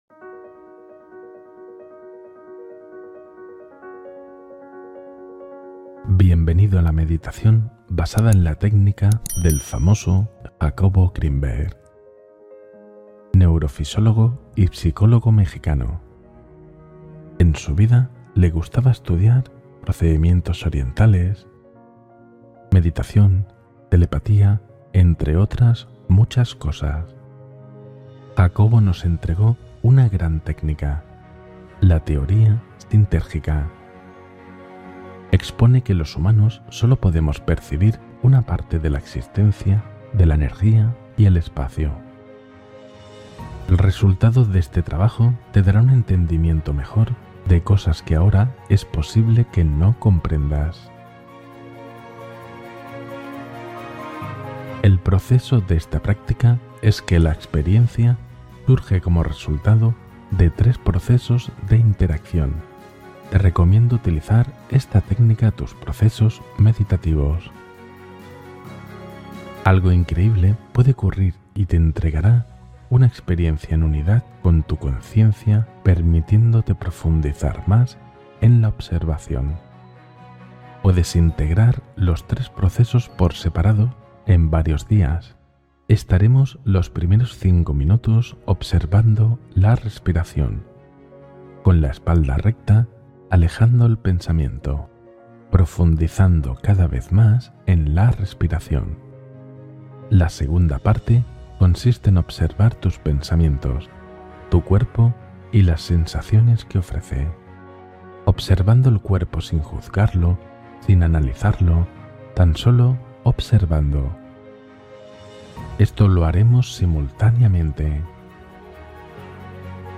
Meditación introspectiva basada en la autoalusión de Jacobo Grinberg